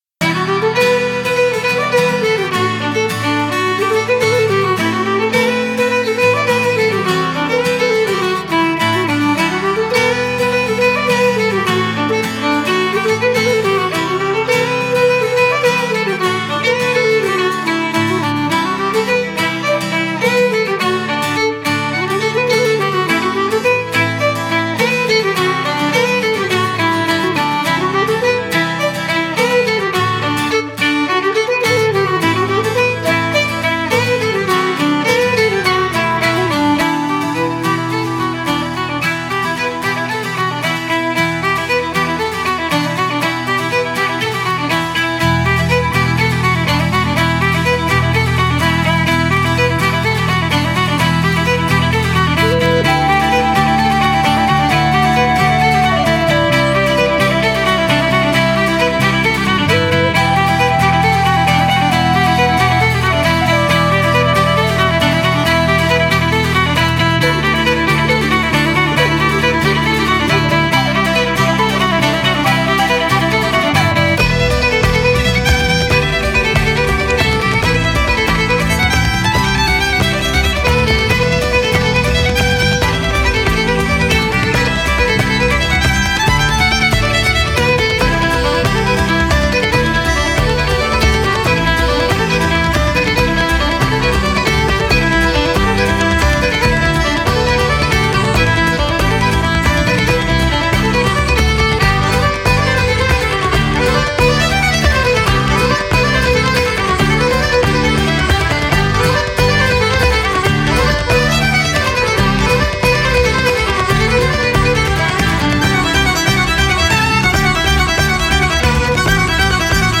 BPM105-139
Polish Celtic band